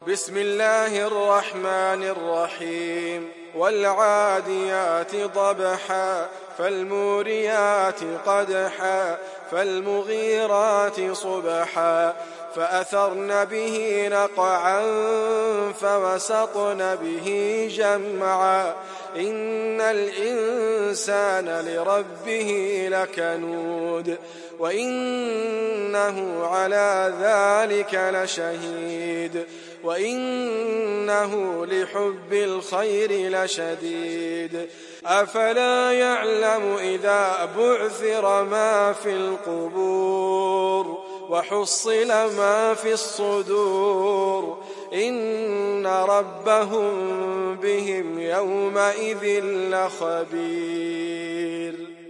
تحميل سورة العاديات mp3 بصوت إدريس أبكر برواية حفص عن عاصم, تحميل استماع القرآن الكريم على الجوال mp3 كاملا بروابط مباشرة وسريعة